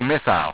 w3_missile.wav